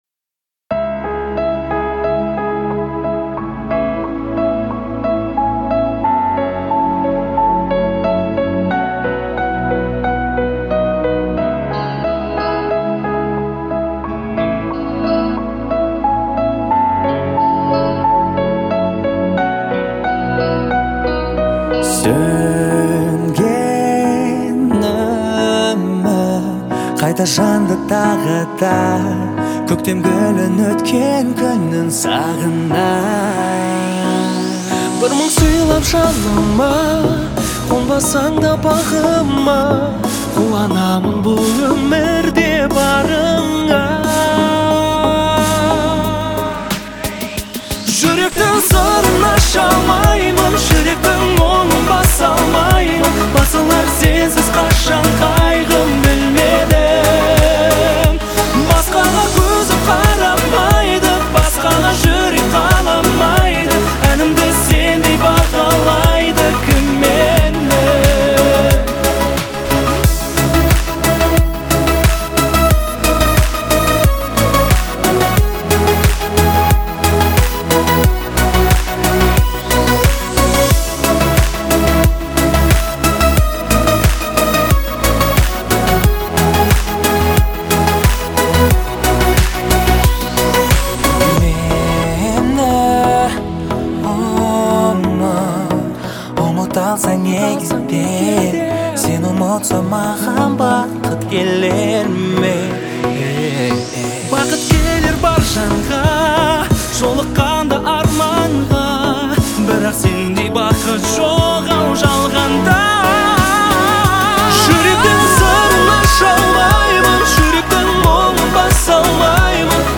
представляет собой трогательный балладный трек в жанре поп.